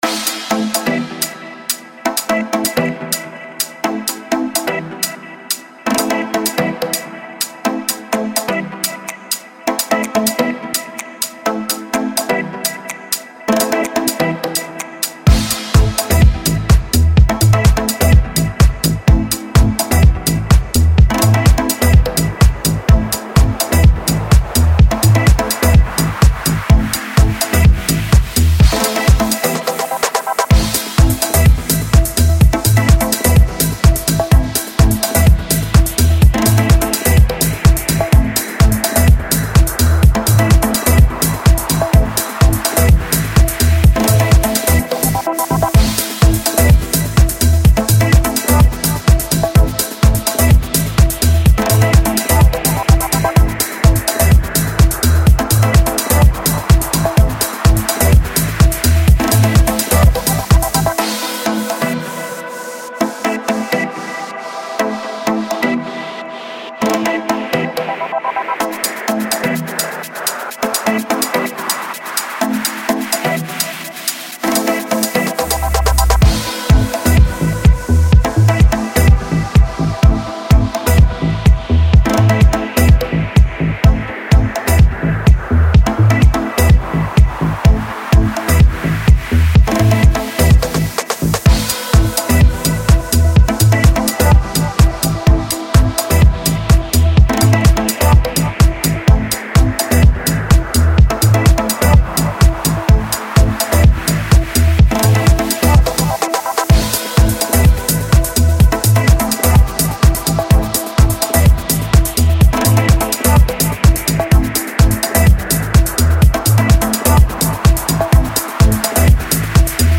Звуковое сопровождение модных показов на подиуме